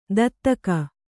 ♪ dattaka